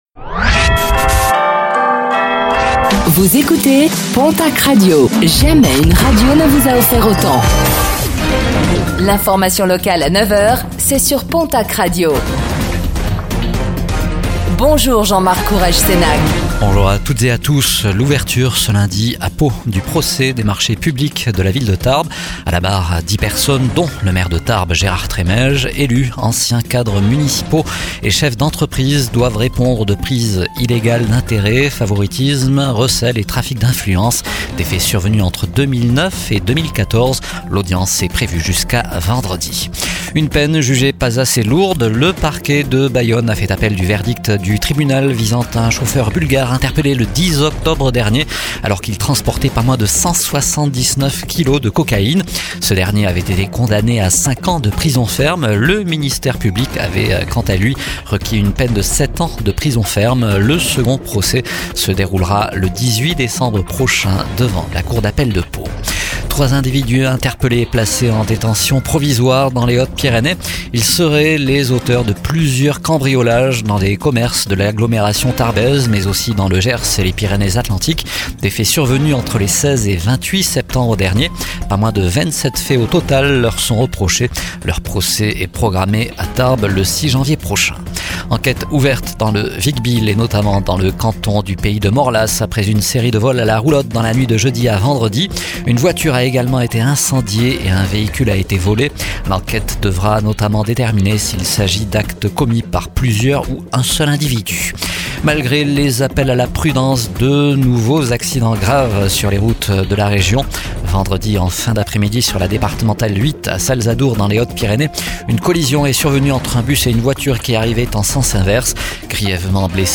09:05 Écouter le podcast Télécharger le podcast Réécoutez le flash d'information locale de ce lundi 17 novembre 2025